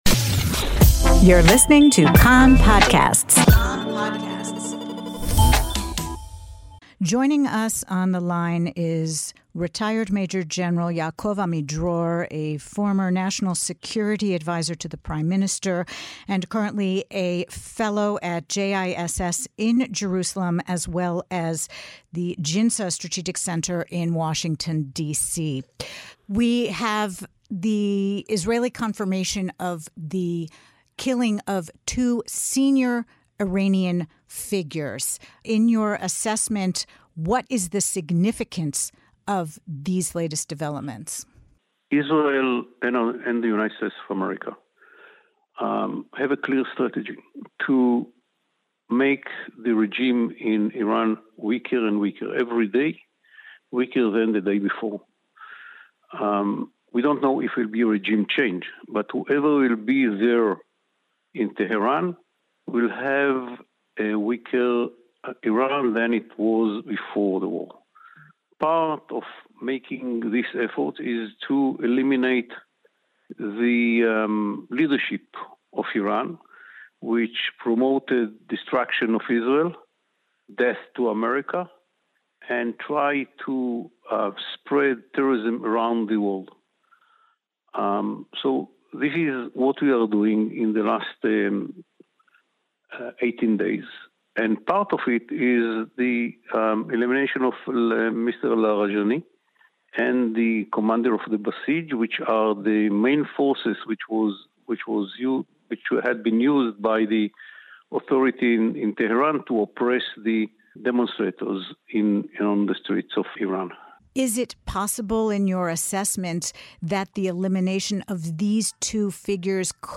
Listen to the full interview by pressing the play button above, or by accessing the interview on Kan’s website by clicking here.